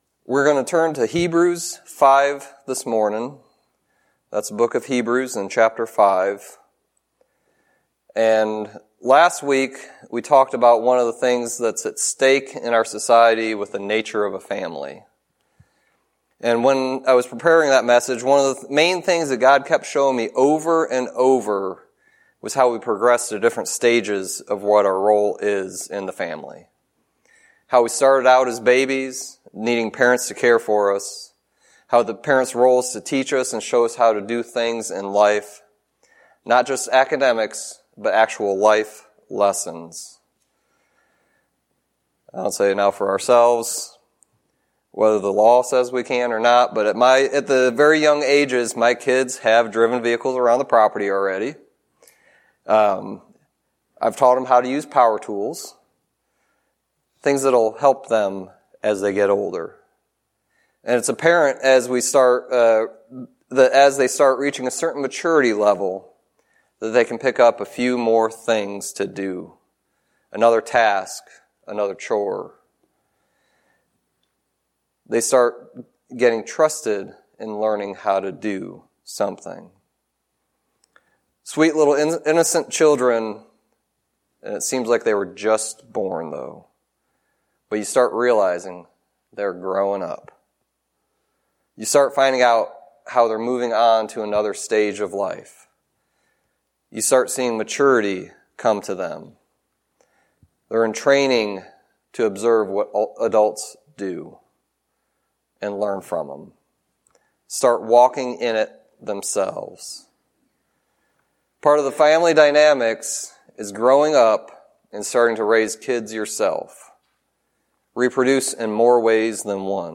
Sermon messages available online.
Hebrews 5:12-14 Service Type: Sunday Teaching It is good for us to desire to grow to be on meat and not milk.